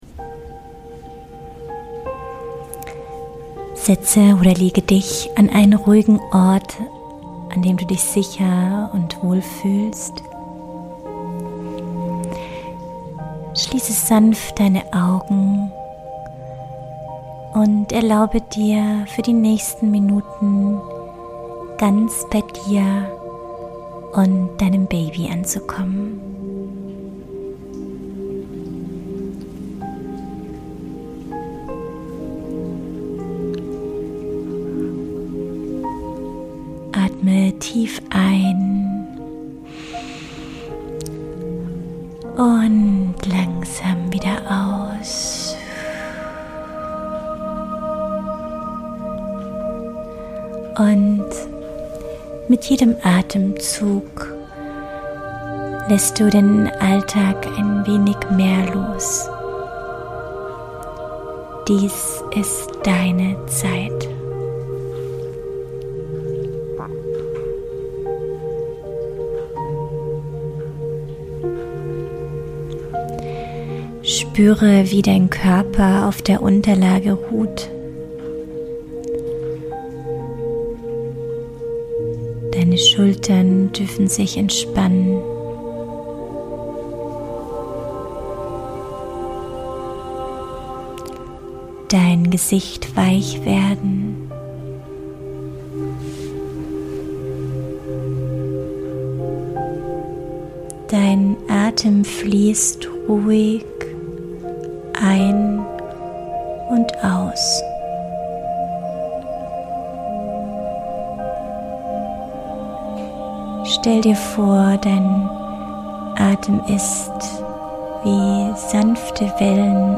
In dieser geführten Meditation wirst du sanft durch Atemübungen und Entspannungsanleitungen geführt, um dich zu beruhigen, Stre...